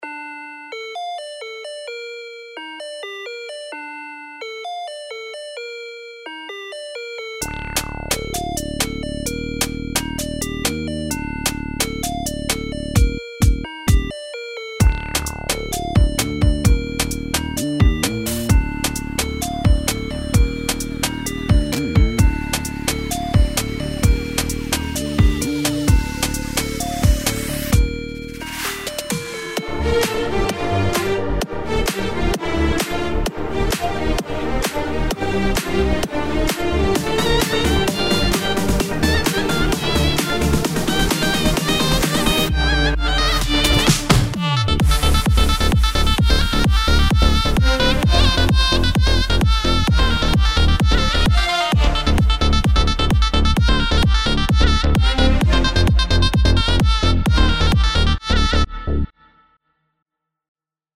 עד שניה 32 לא אהבתי בכלל את הבחירת סאונדים, צריך סאונדים יותר עסיסים ובועטים עם קצת רוורב/ENDELSS SMILE החל משניה 32 פצצה לגמריי...